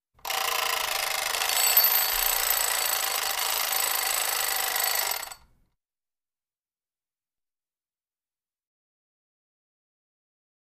Clock Alarm Old 3; Rings With Bell And Ratty Fast Clunks 1